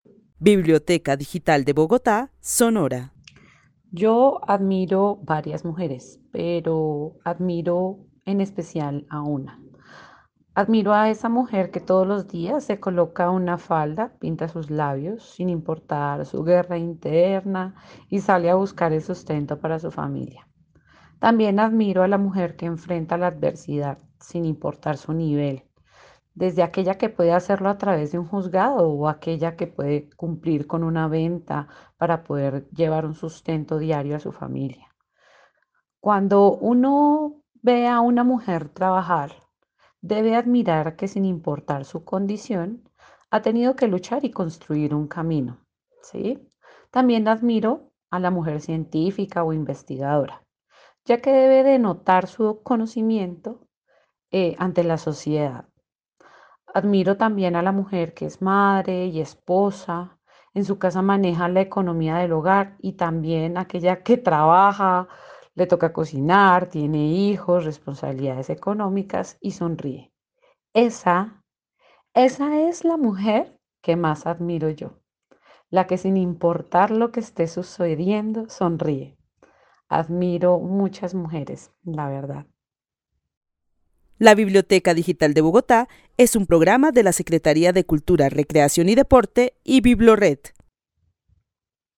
Narración oral de una mujer que vive en la ciudad de Bogotá quien admira a todas las mujeres, pero especialmente a una mujer que se pone falda y se pinta los labios sin importar la guerra interna que este viviendo y sale a buscar el sustento para su familia. Admira a la mujer que enfrenta la adversidad sin importar su nivel, sea científica, madre, esposa, trabajadora.
El testimonio fue recolectado en el marco del laboratorio de co-creación "Postales sonoras: mujeres escuchando mujeres" de la línea Cultura Digital e Innovación de la Red Distrital de Bibliotecas Públicas de Bogotá - BibloRed.
Narrativas sonoras de mujeres